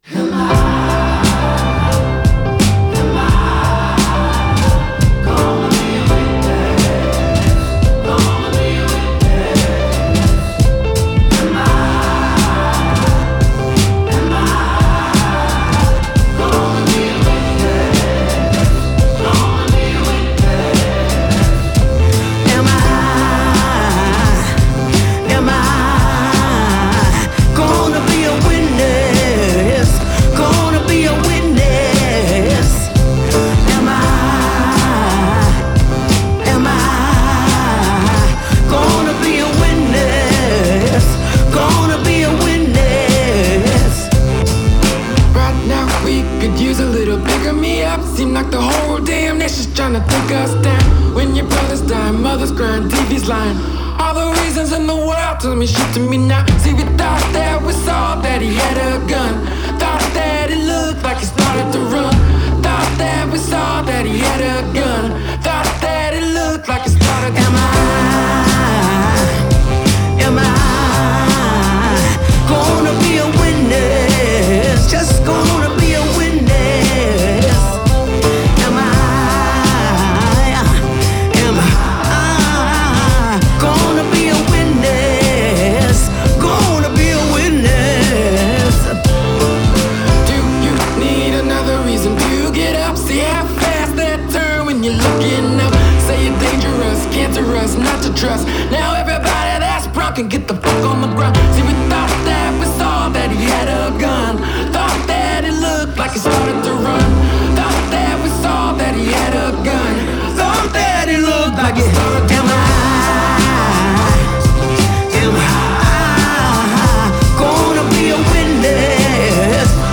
soulful rhythm and blues